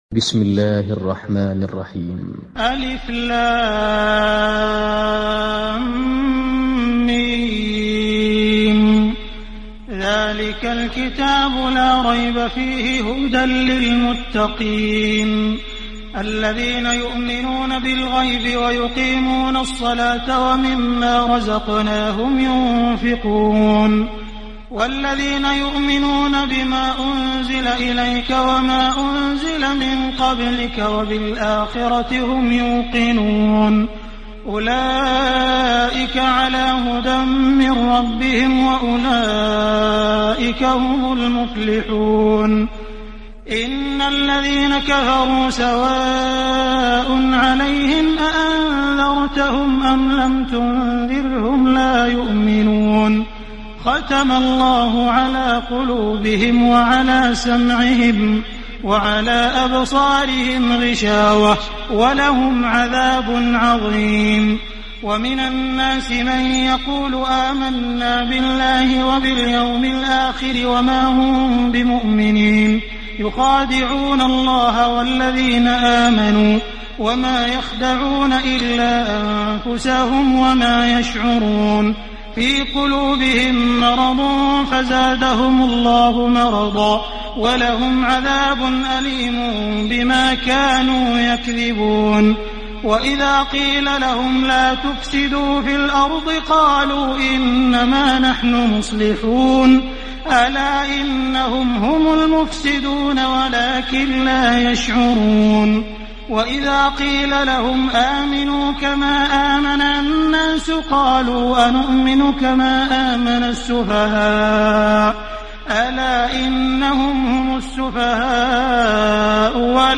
Bakara Suresi İndir mp3 Abdul Rahman Al Sudais Riwayat Hafs an Asim, Kurani indirin ve mp3 tam doğrudan bağlantılar dinle